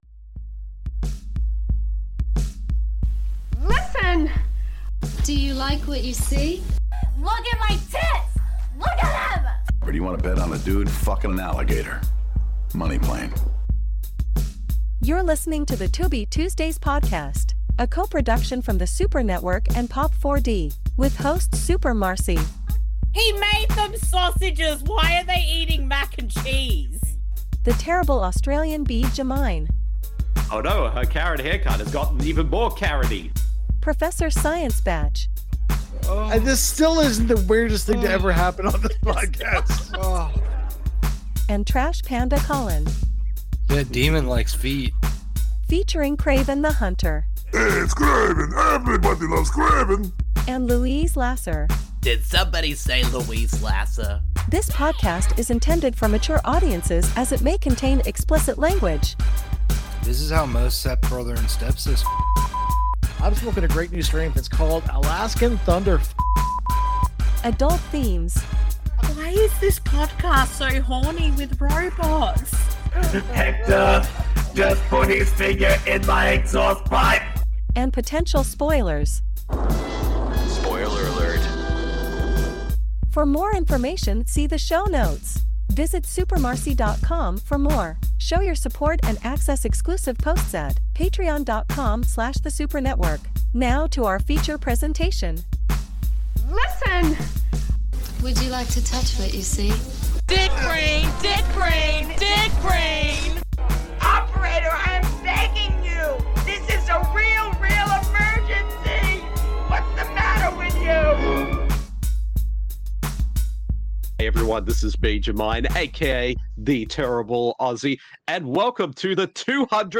The Tubi Tuesdays Podcast Episode 200 – Mac and Me (1988) Recorded Live